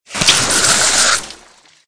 AA_squirt_neonwatergun.ogg